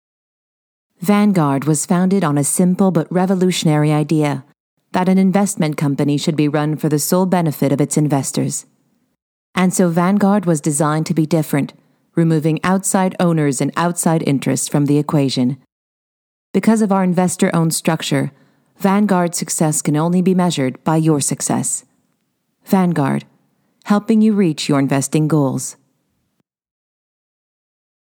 Narration - ANG